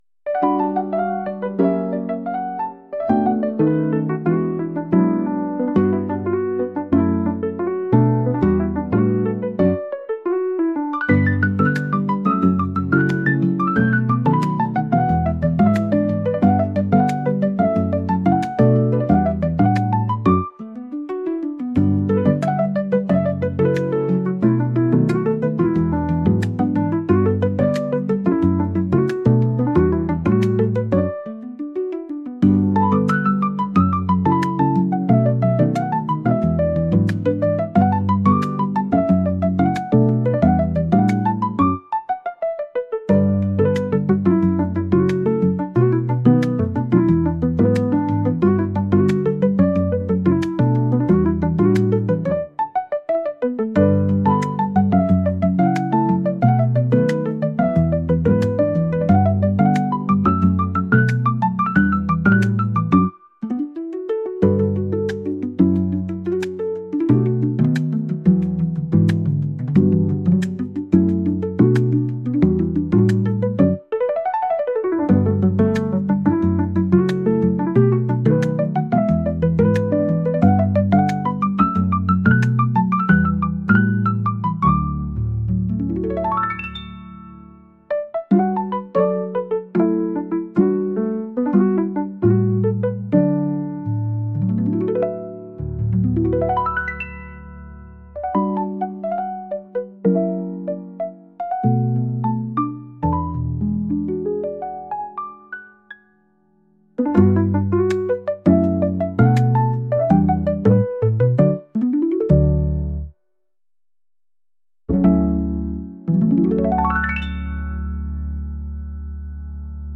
週末を過ごすジャズのピアノ曲です。